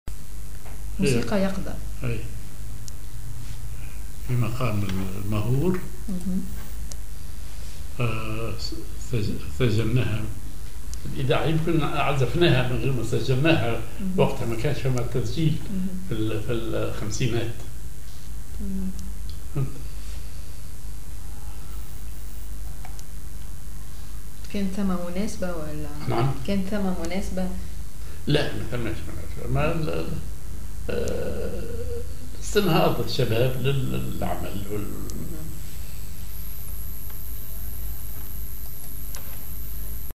Maqam ar ماهور
معزوفة موسيقية